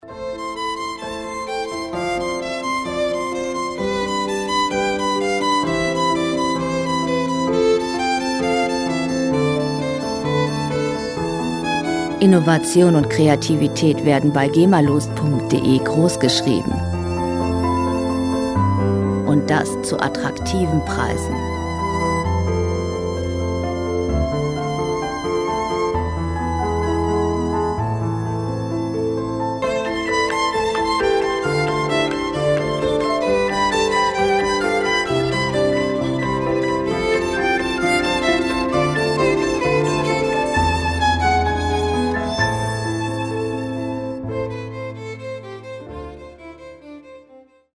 Flügel und Geige
• Weihnachtliches Duett im Barock-Stil